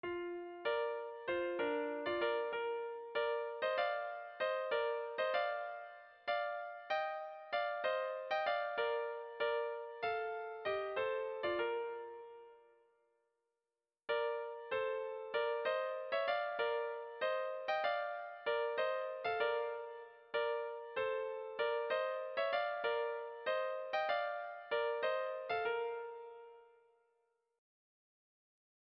Dantzakoa
Seiko txikia (hg) / Hiru puntuko txikia (ip)
ABDE